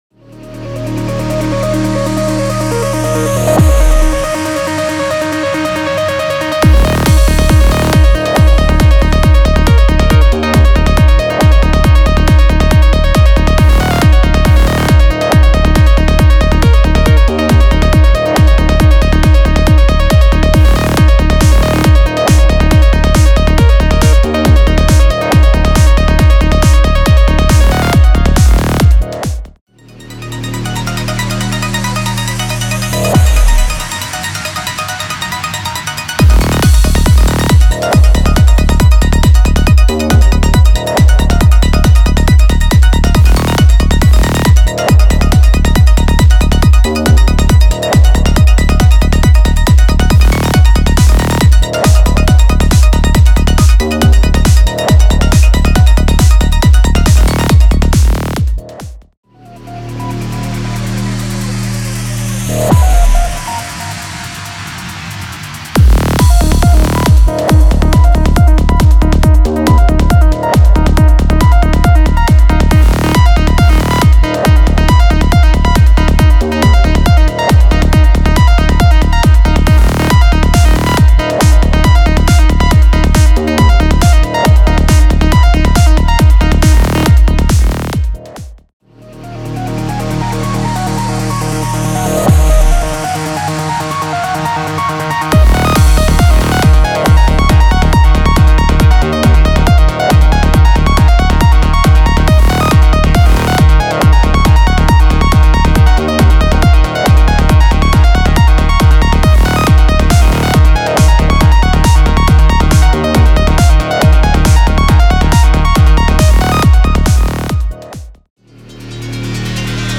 Psy-Trance Trance